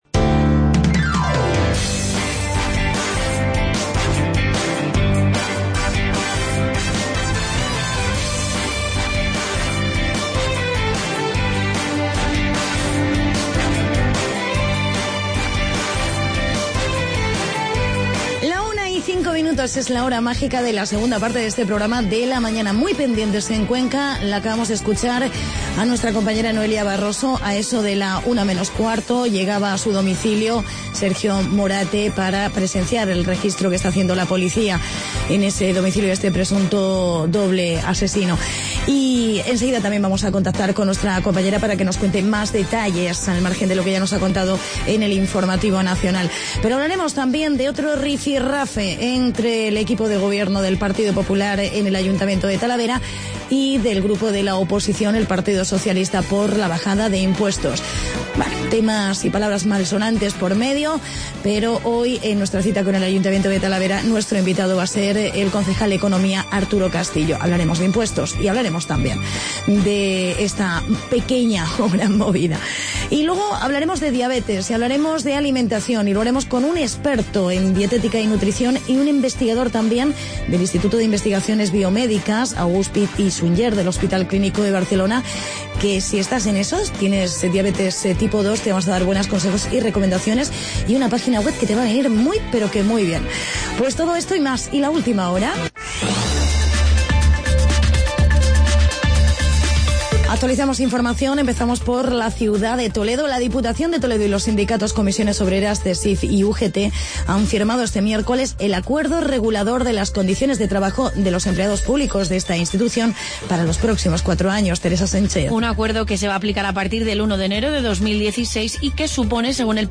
Entrevista con el concejal Arturo Castillo.